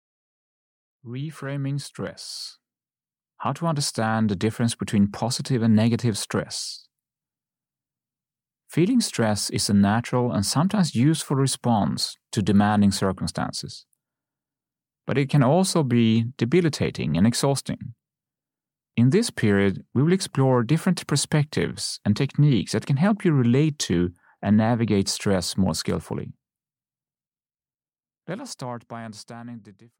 Reframing Stress (EN) audiokniha
Ukázka z knihy